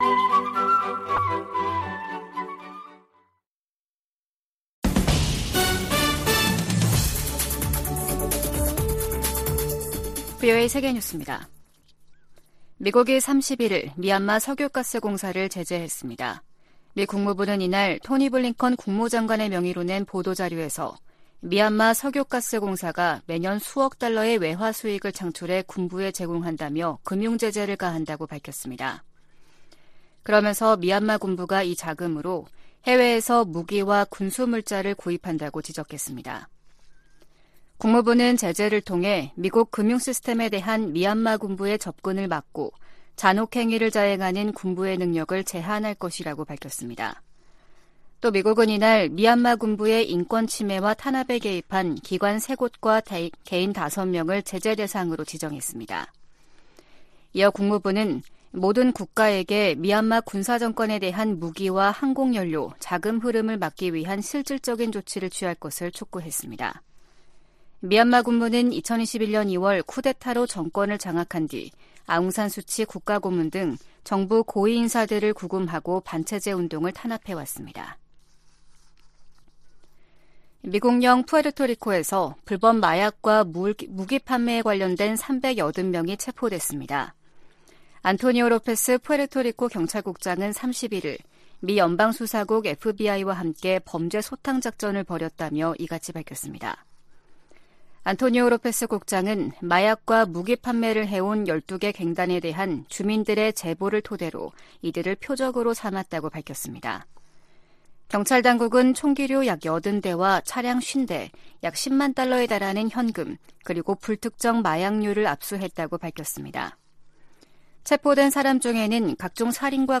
VOA 한국어 아침 뉴스 프로그램 '워싱턴 뉴스 광장' 2023년 11월 1일 방송입니다. 미 국무부 대북특별대표가 중국 한반도사무 특별대표와 화상회담하고 북러 무기거래가 비확산 체제를 약화시킨다고 지적했습니다. 하마스가 북한제 무기를 사용했다는 정황이 나온 가운데 미 하원 외교위원장은 중국·이란에 책임을 물어야 한다고 주장했습니다. 북한의 핵 위협에 대한 한국 보호에 미국 핵무기가 사용될 것을 확실히 하는 정책 변화가 필요하다는 보고서가 나왔습니다.